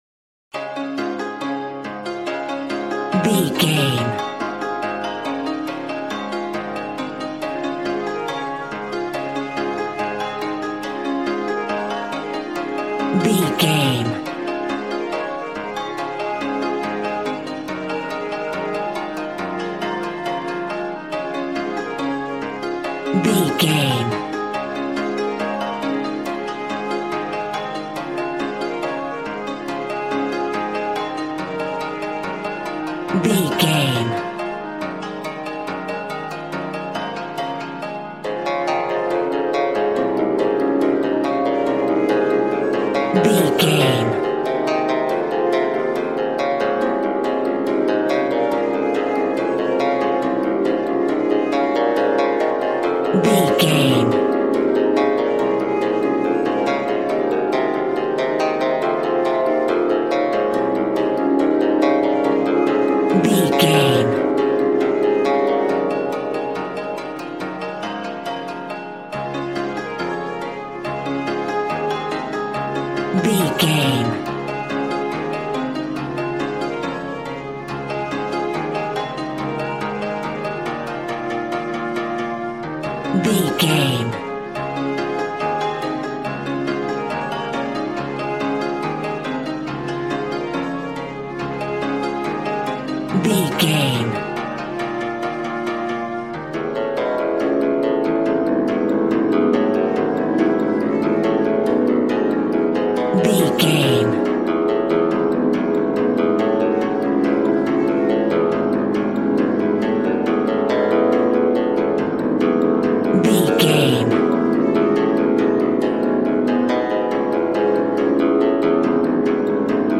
Ionian/Major
A♭
smooth
conga
drums